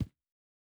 Shoe Step Stone Hard C.wav